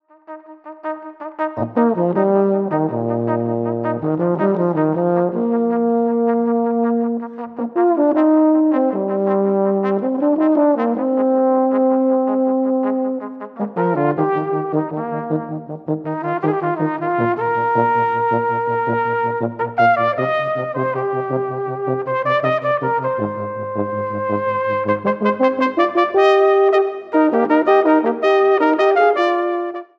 Gattung: für Flügelhorn und Tenorhorn
Besetzung: VOLKSMUSIK Weisenbläser